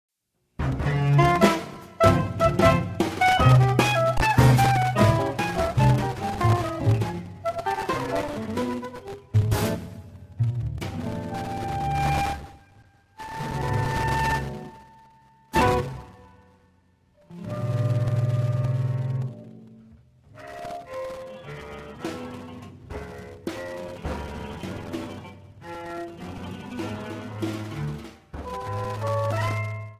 Processed Guitar
Woodwinds
Bass
Drums